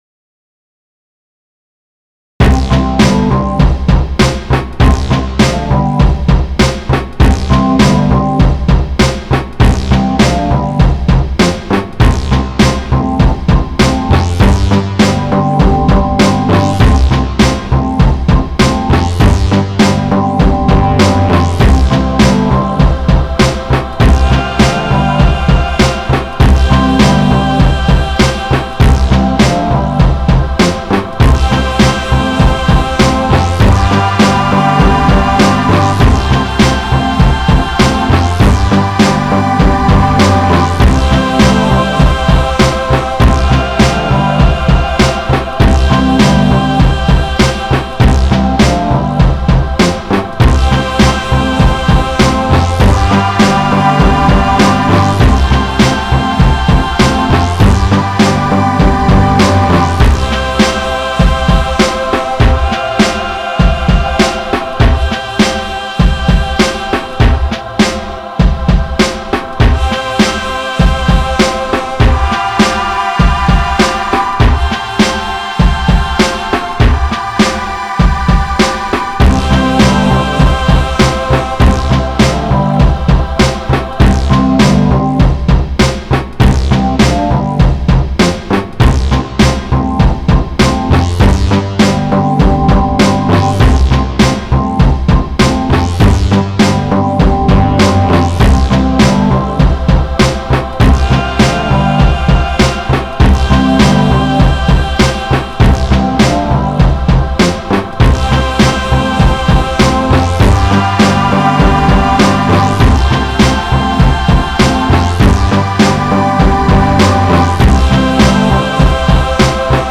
I just recently obtained the Maschine Mirko MK3.
I immediately started playing around with it and produced a nice progressive uplifting instrumental.